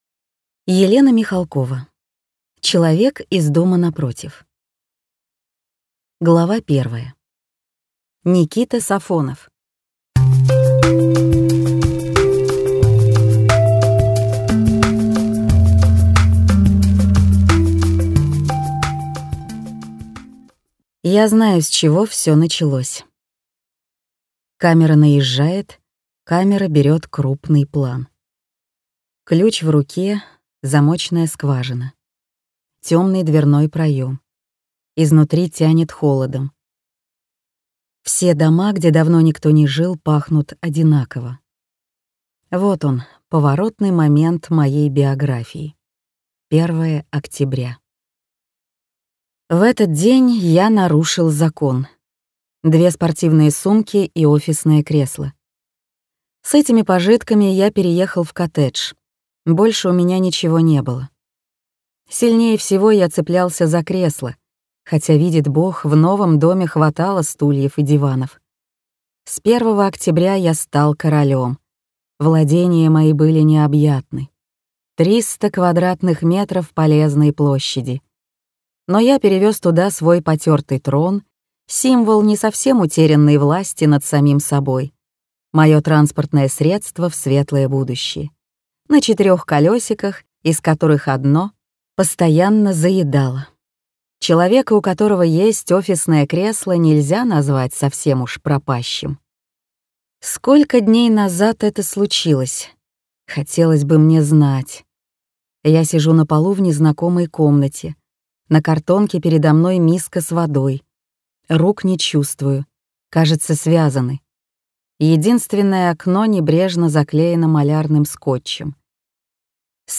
Аудиокнига «Человек из дома напротив» в интернет-магазине КнигоПоиск ✅ в аудиоформате ✅ Скачать Человек из дома напротив в mp3 или слушать онлайн